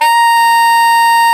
SAX A.FF A0M.wav